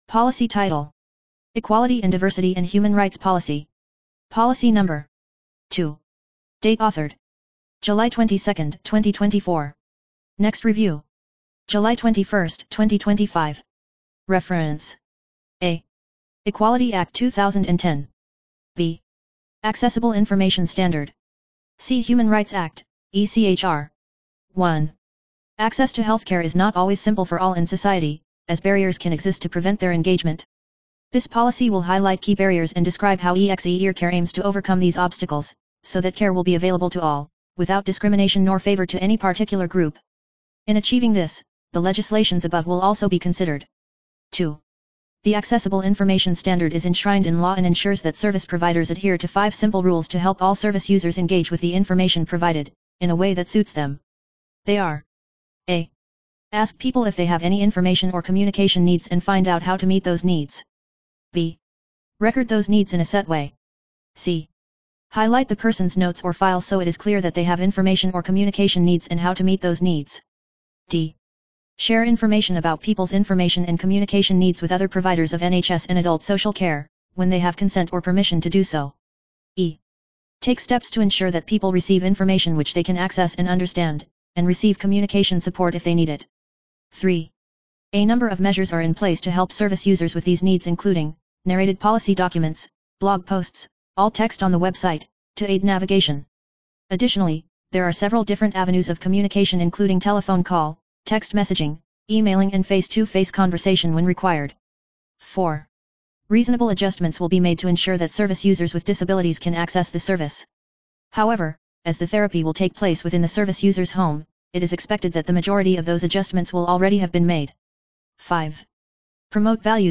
narraation of equality & diversity and echr policy